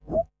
short_jump.wav